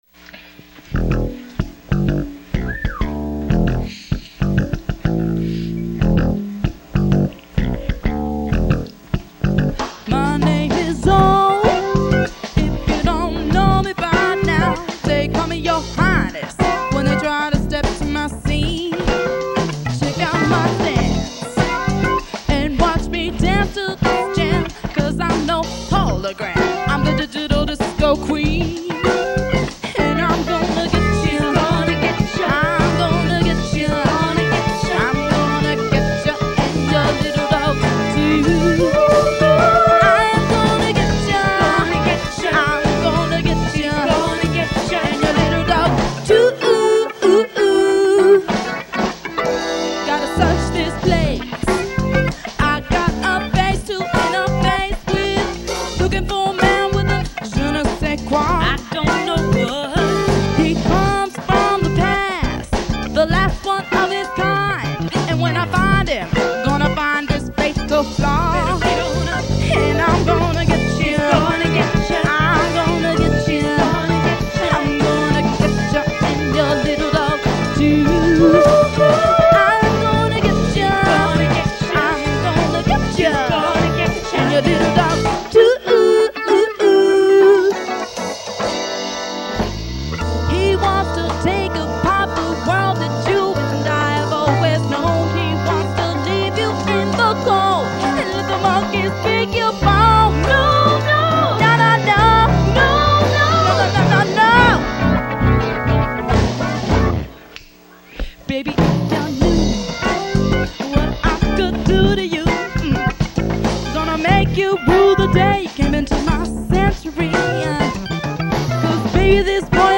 (original cast 4-track version)